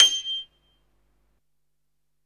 SG1 PNO  G 6.wav